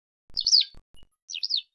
Alauda arvensis - Skylark - Allodola
call1.wav